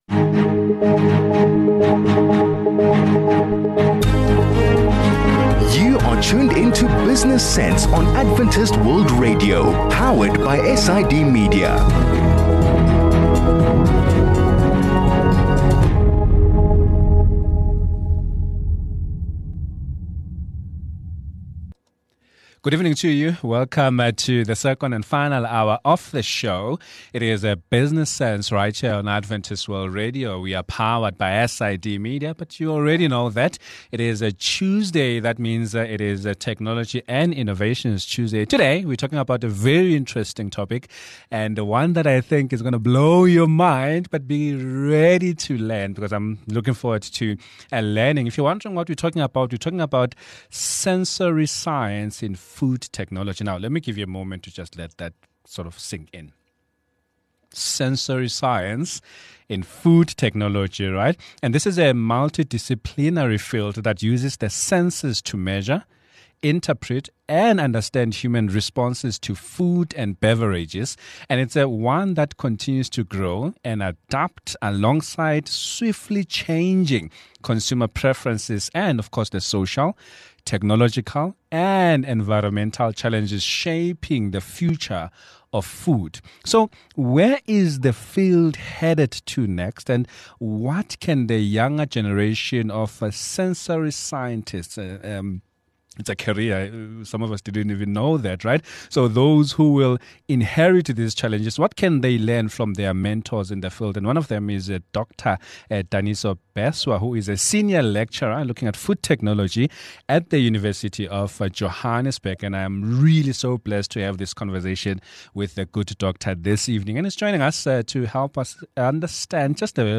In today’s conversation, we explore the future of sensory analysis as it relates to food technology.